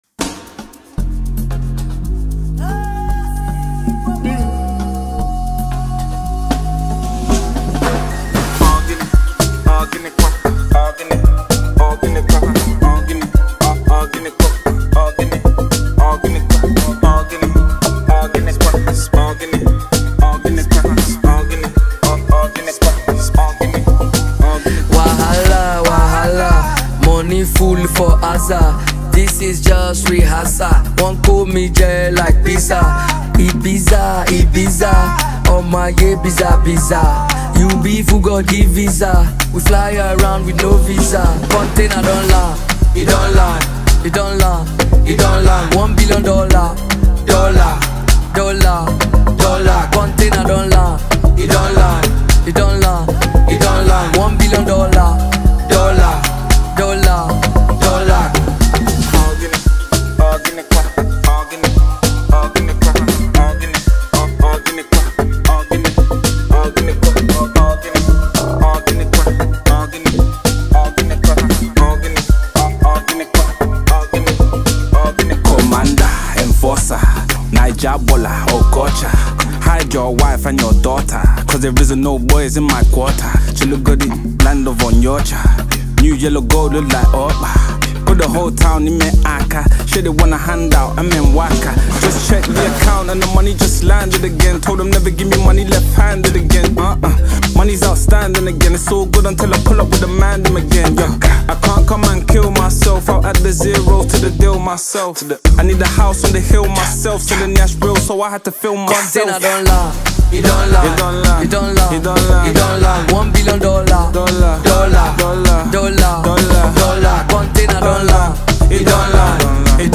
” an interesting melody.